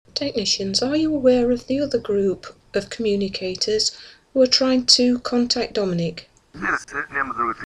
Here are some results recorded in a session we did earlier.